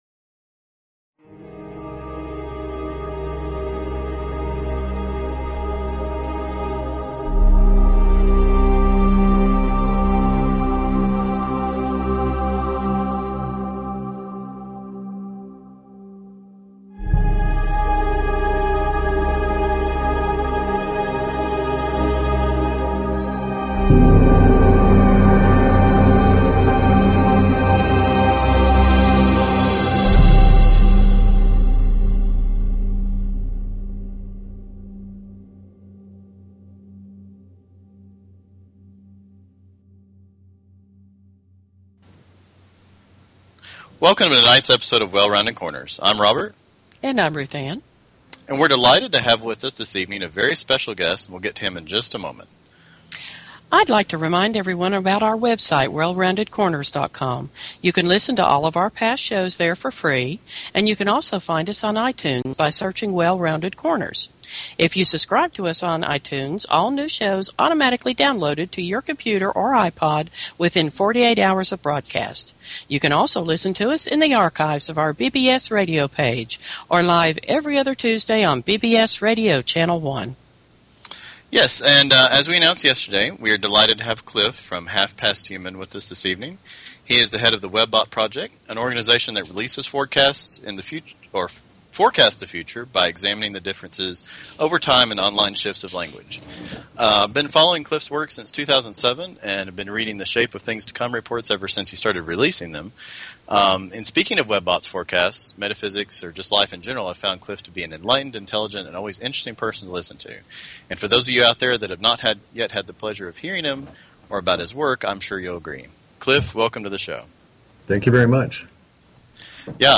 Talk Show Episode, Audio Podcast, Well_Rounded_Corners and Courtesy of BBS Radio on , show guests , about , categorized as